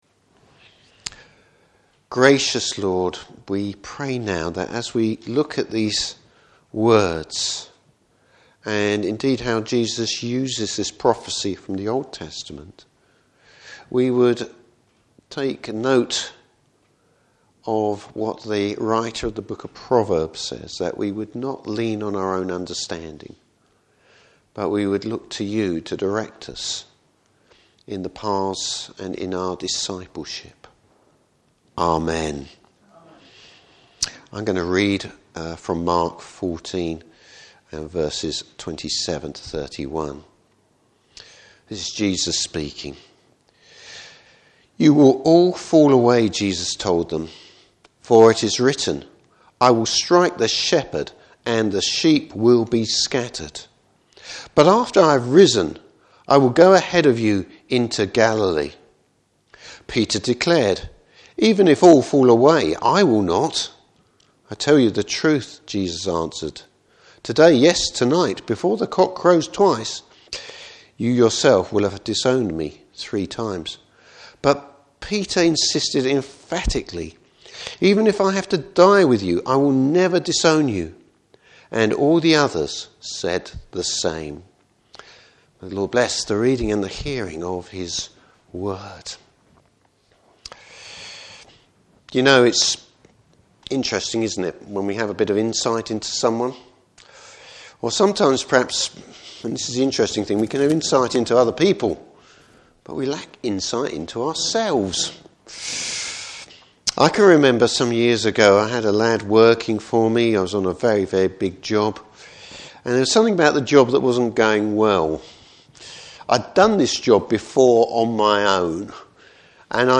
Service Type: Morning Service Peter fails to recognise his weakness.